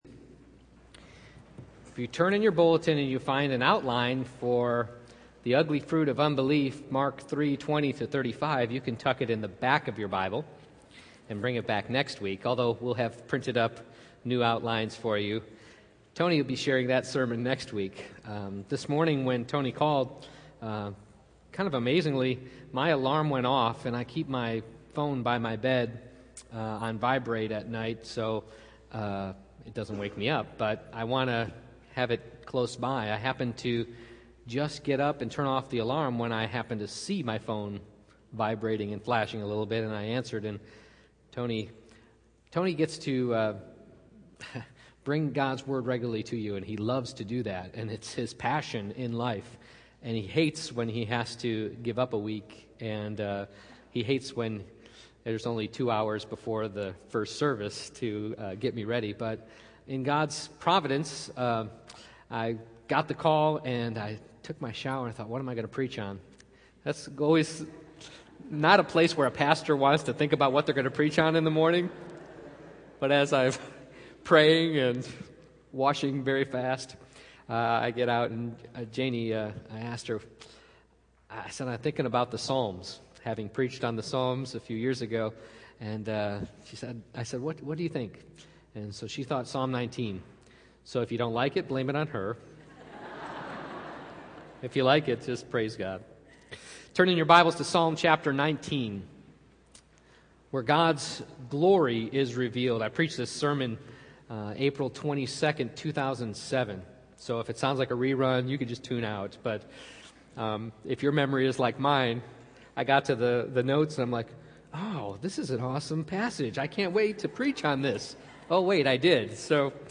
Passage: Psalm 19:1-14 Service Type: Morning Worship Download Files Bulletin « People